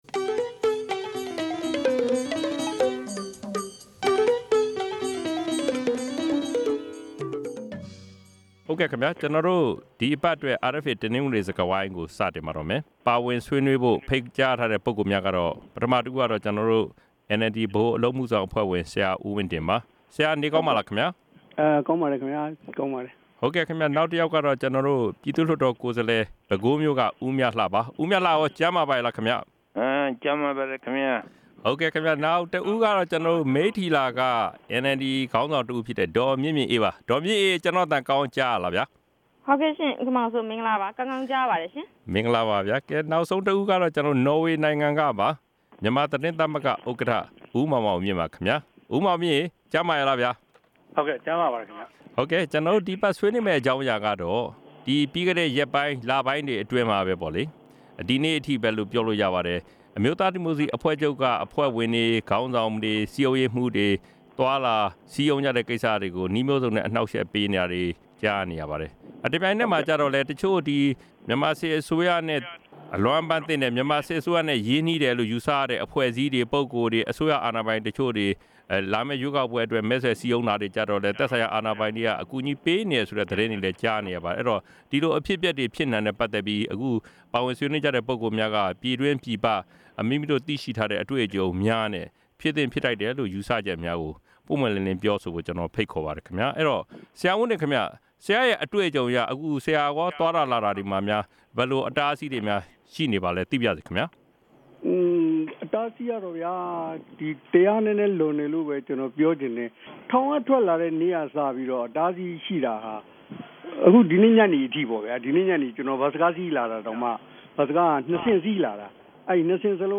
တနဂဿေိံစြကားဝိုင်း။